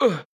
damage3.wav